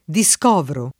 diSk0vro]; coniug. sim.